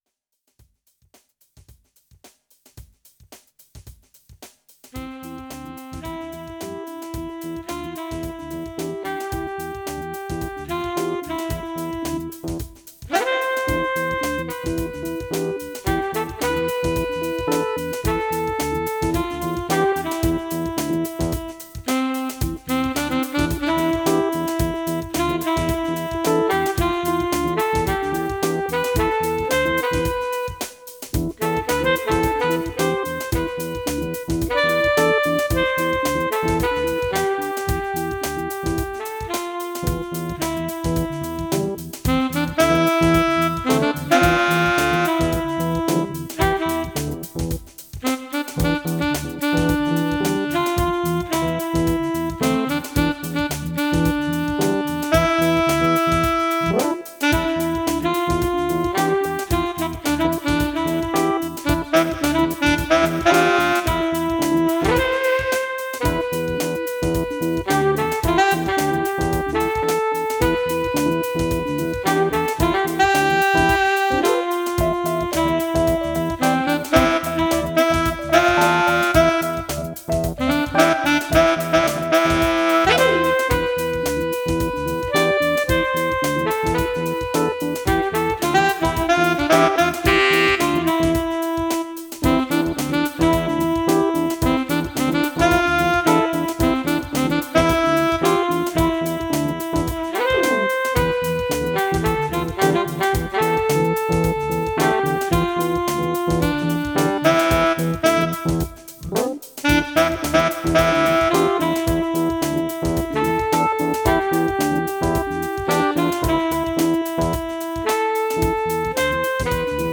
Tempo: 104 bpm / Datum: 08.11.2017